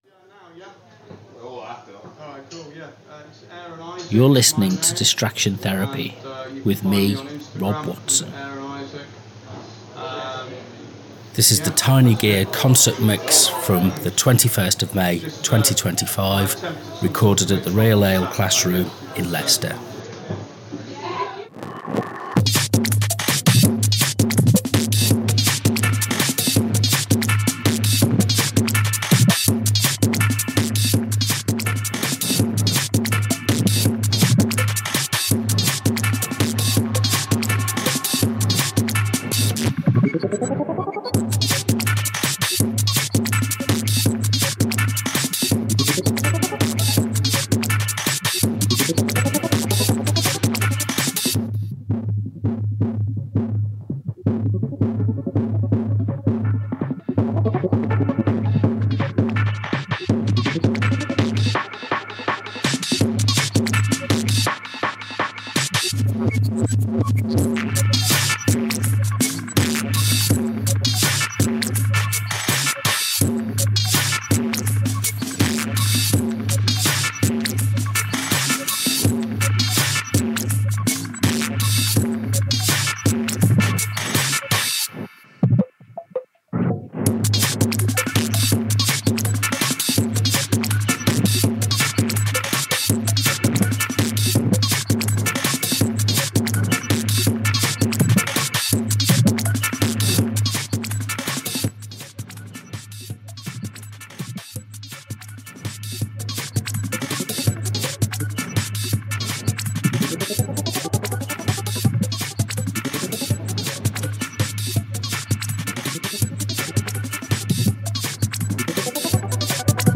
On 21st May 2025, the Real Ale Classroom hosted the Tiny Gear Concert—a gathering of artists, listeners, and audio experimenters drawn together by their shared love of compact equipment and big ideas.
The evening featured an eclectic mix of performances: modular synth improvisations, handheld cassette loops, lo-fi ambient textures, field recordings, and beat-based narratives. Each set used minimal kit—pocket synths, samplers, contact mics—but explored expansive sonic terrain. From pulsing minimalism to playful noise, the event was a celebration of constraint as creative freedom.
The whole night was recorded live and will form the basis of an upcoming Radio Lear feature, sharing the sounds and reflections of those who make music on their own terms, with whatever they have to hand.